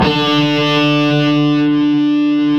Index of /90_sSampleCDs/Roland - Rhythm Section/GTR_Distorted 1/GTR_Power Chords